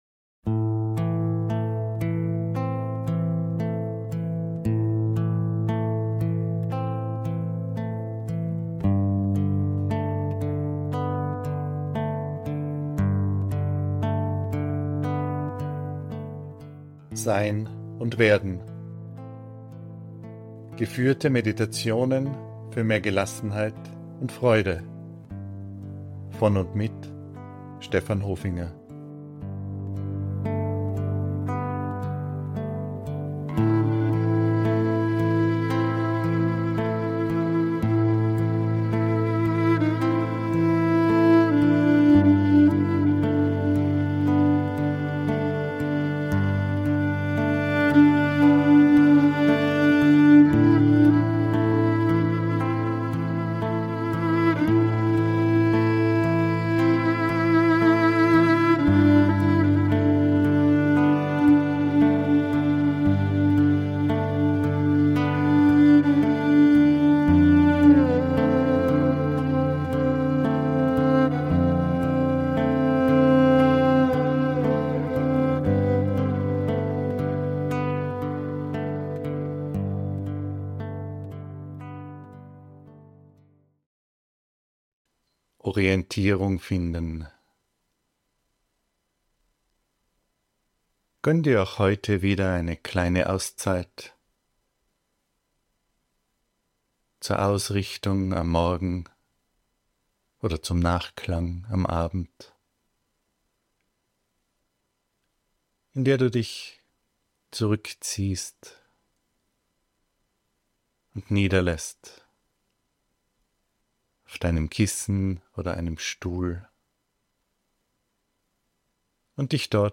Meditationen für mehr Gelassenheit und Freude